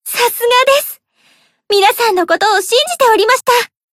贡献 ） 分类:蔚蓝档案语音 协议:Copyright 您不可以覆盖此文件。
BA_V_Tomoe_Battle_Victory_2.ogg